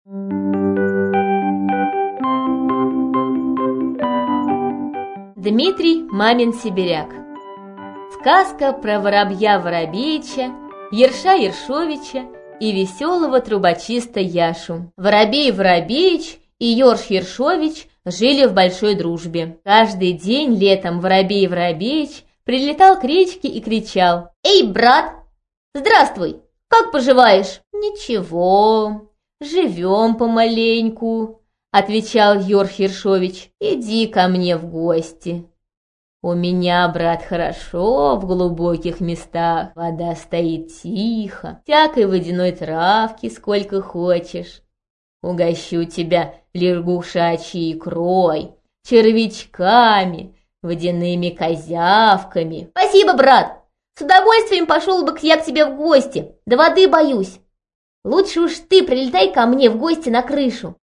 Аудиокнига Сказка про Воробья Воробеича, Ерша Ершовича и весёлого трубочиста Яшу | Библиотека аудиокниг